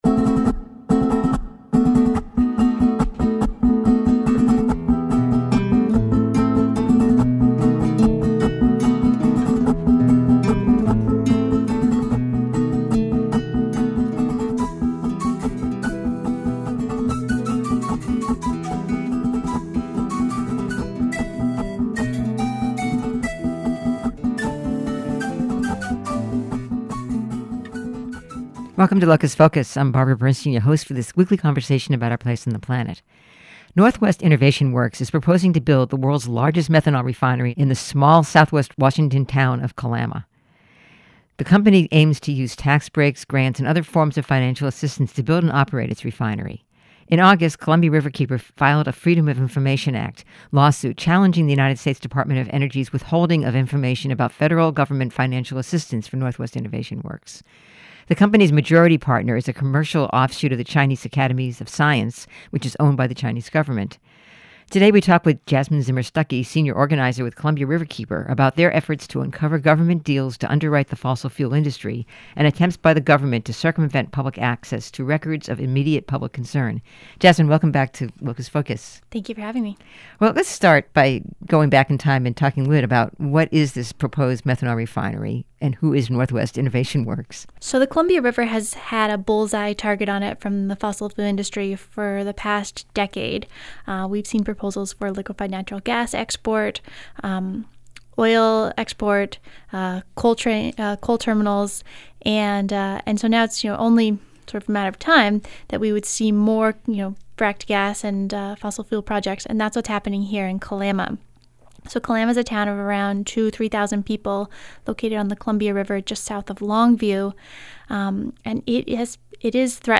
Kalama Methanol Refinery on the Public Dole | KBOO Listen Now